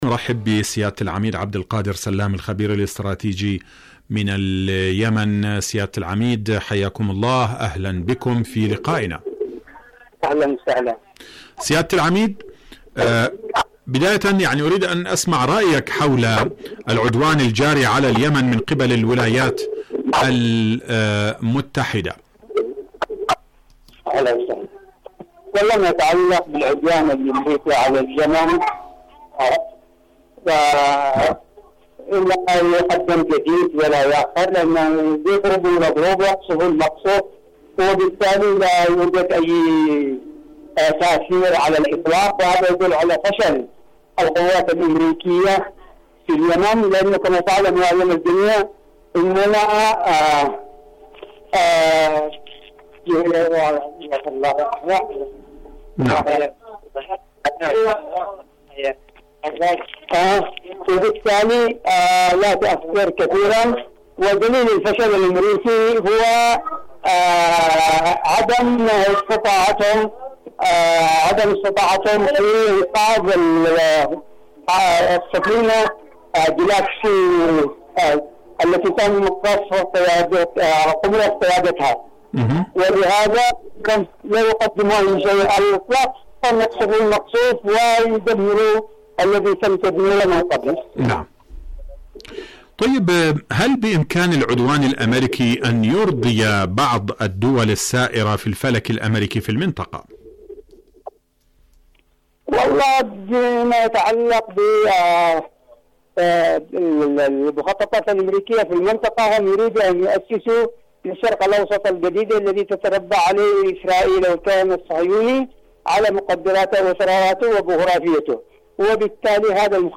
مقابلات إذاعية برنامج حدث وحوار